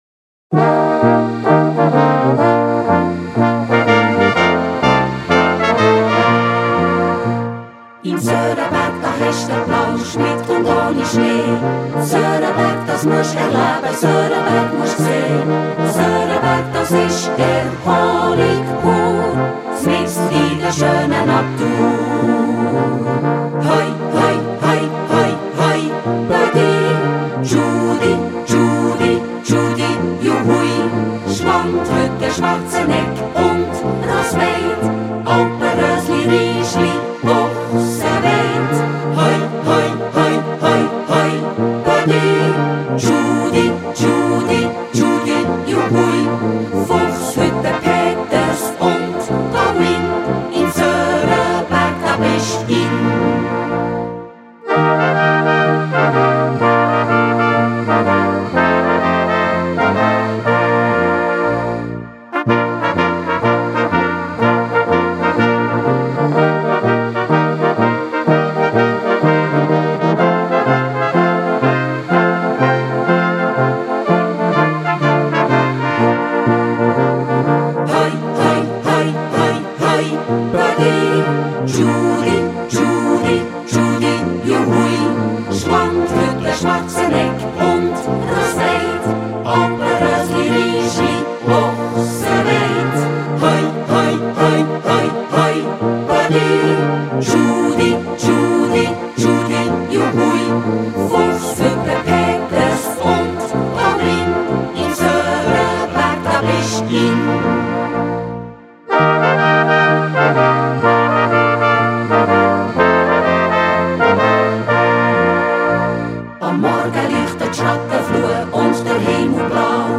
Gattung: für Jugendblasorchester
3:00 Minuten Besetzung: Blasorchester PDF